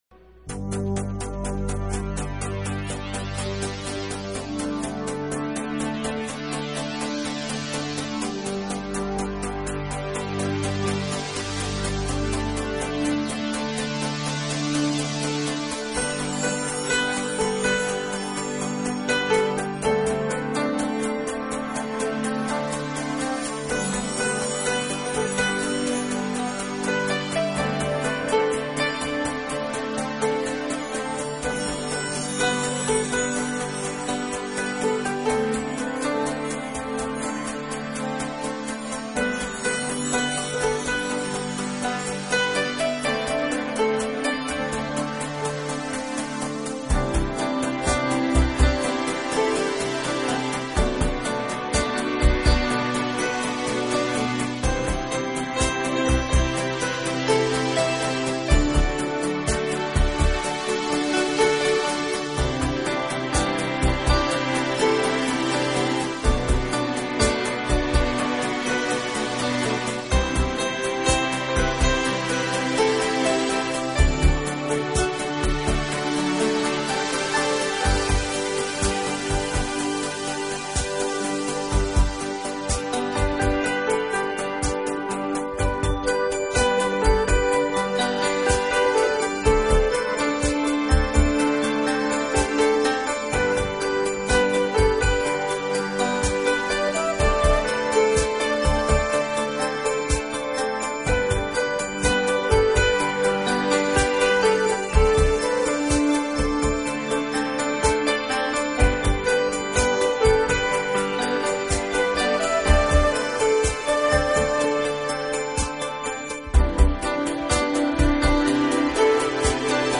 专辑语言：纯音乐
这种音乐是私密的，轻柔的，充满庄严感并总是令人心胸开阔。
实为New Age音乐发展中令人惊喜的成果。